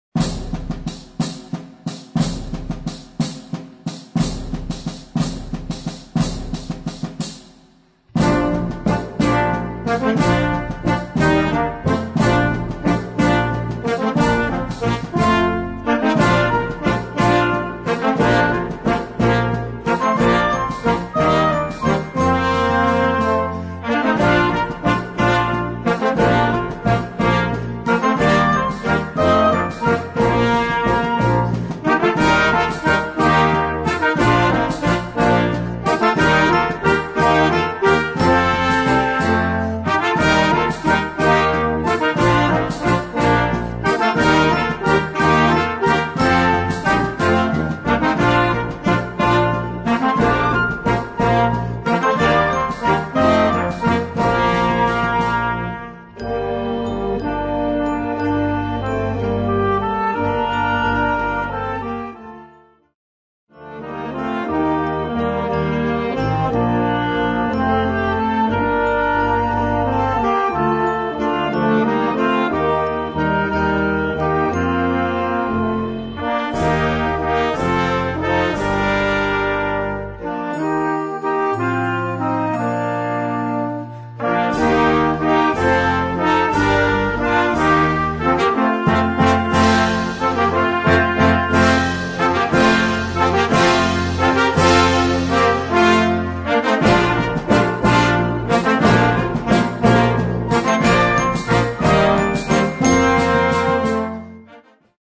Noten für flexibles Jugend Ensemble, 4-stimmig + Percussion.